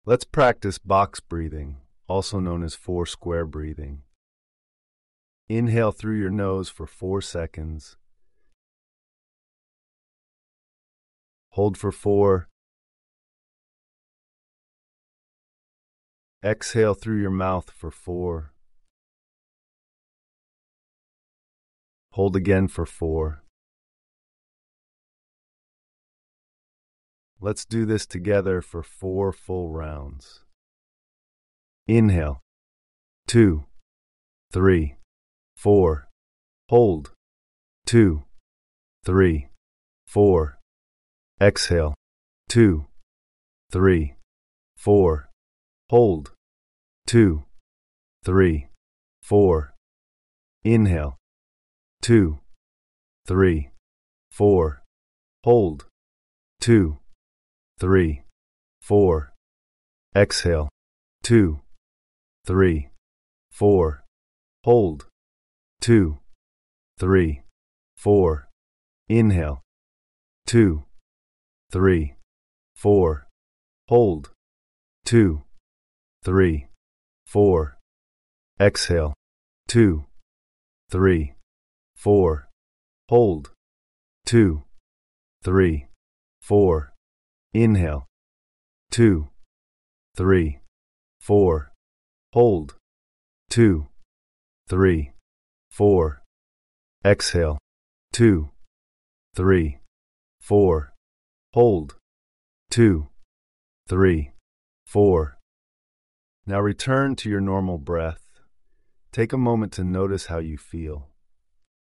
Press play to experience a calming 2-minute box breathing practice you can use anytime you need to refocus.